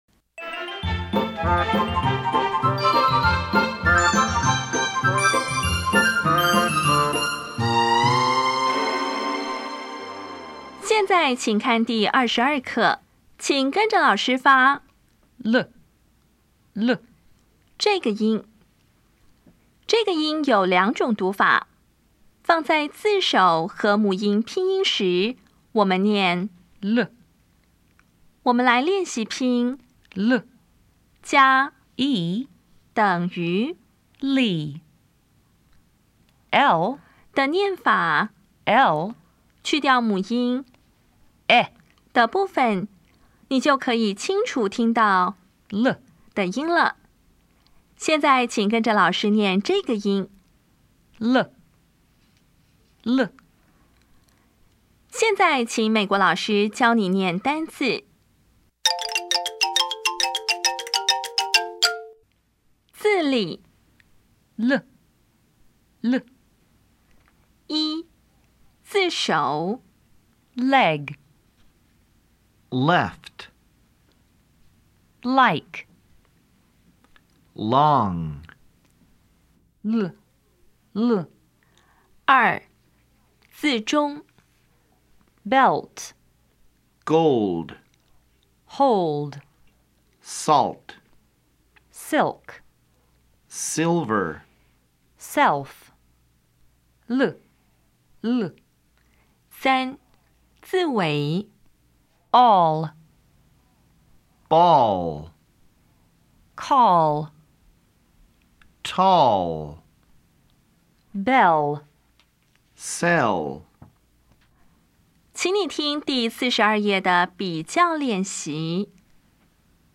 当前位置：Home 英语教材 KK 音标发音 子音部分-2: 有声子音 [l]
音标讲解第二十二课
比较[l][r]       [l] (有声/不卷舌)  [r] (有声/卷舌)
Listening Test 11